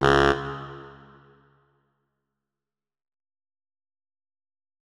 main Divergent / mods / Hideout Furniture / gamedata / sounds / interface / keyboard / saxophone / notes-13.ogg 48 KiB (Stored with Git LFS) Raw Permalink History